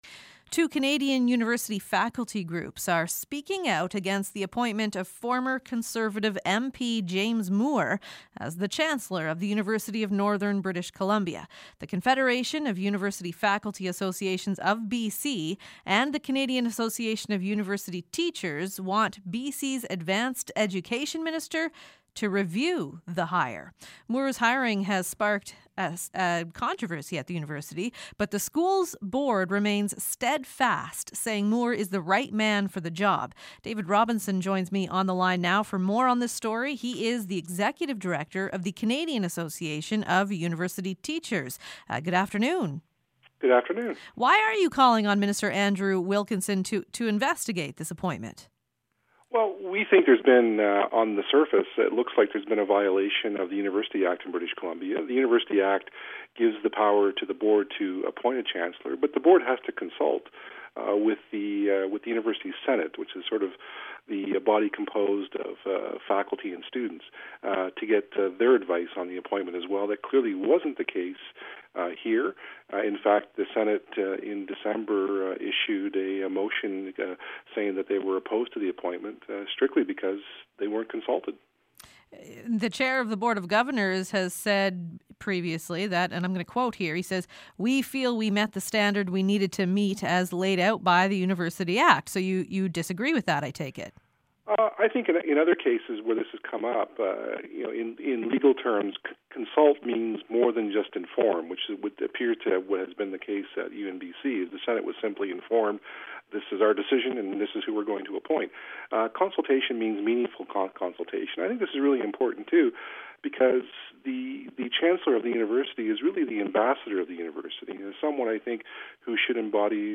joins me on the line now for more on this story.